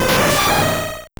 Cri de Cizayox dans Pokémon Or et Argent.